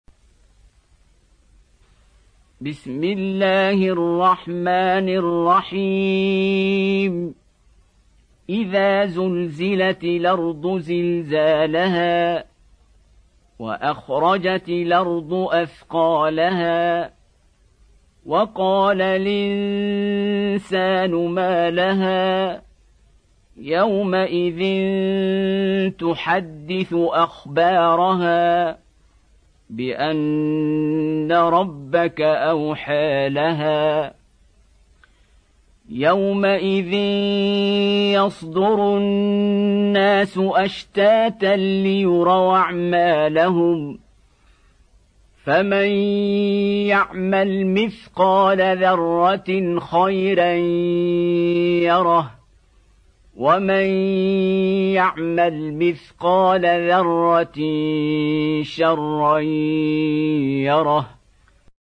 Surah Az Zalzalah Beautiful Recitation MP3 Download By Qari Abdul Basit in best audio quality.